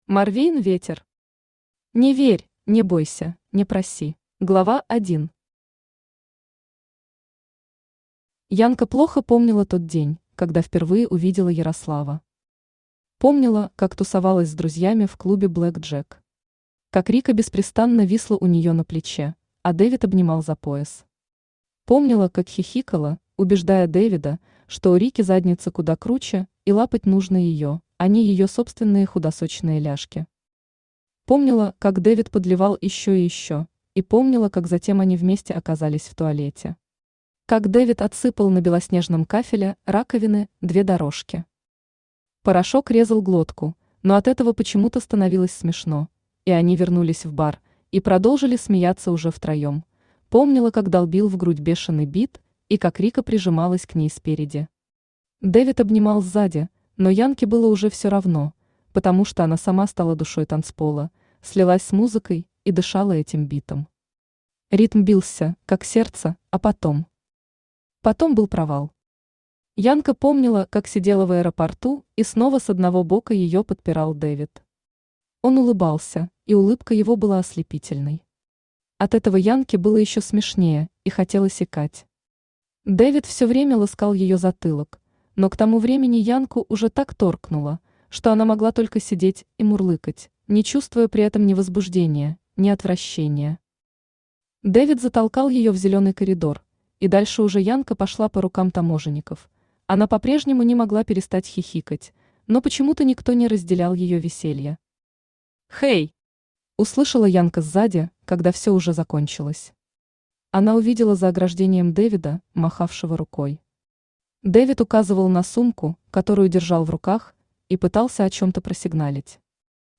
Аудиокнига Не верь, не бойся, не проси | Библиотека аудиокниг
Aудиокнига Не верь, не бойся, не проси Автор Морвейн Ветер Читает аудиокнигу Авточтец ЛитРес.